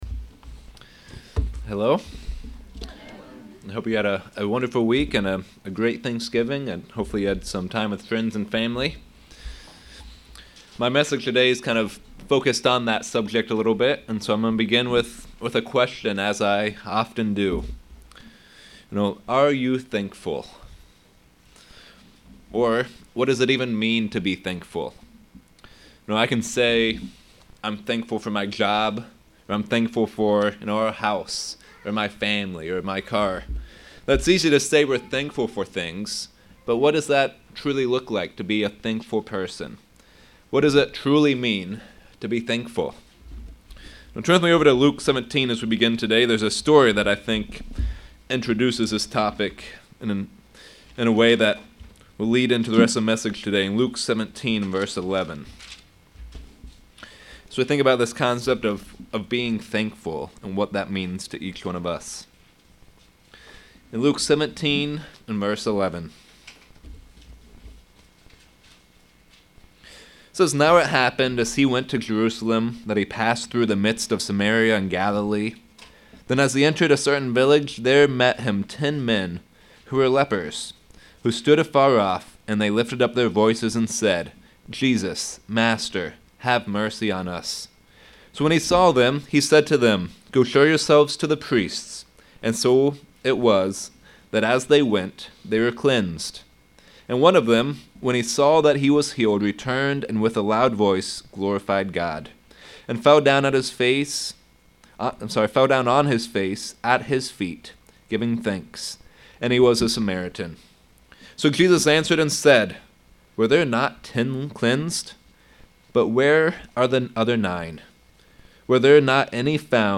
Sermons
Given in York, PA Lewistown, PA